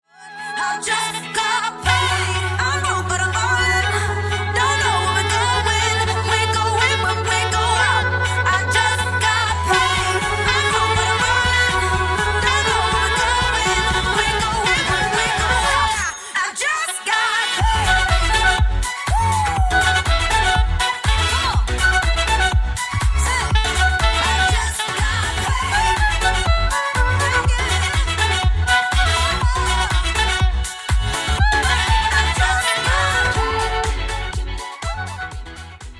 DJ, Violin, Sax or Female Vocals